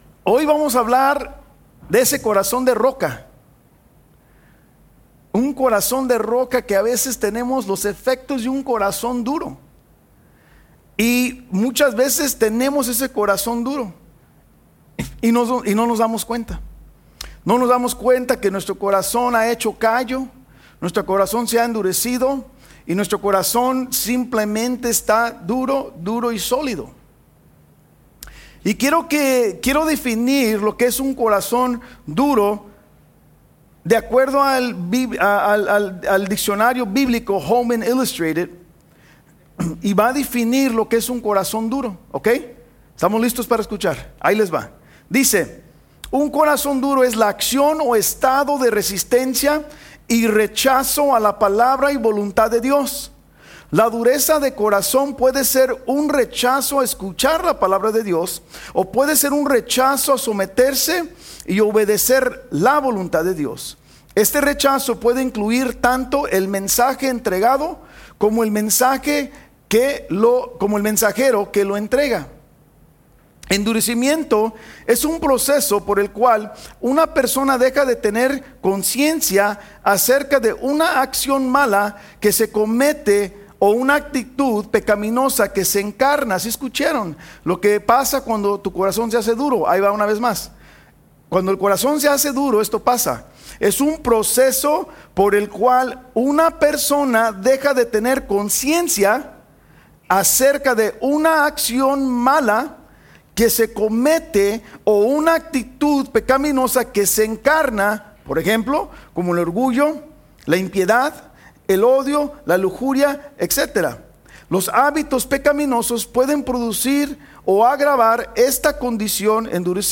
Estudio Biblico | Iglesia Vida Hammond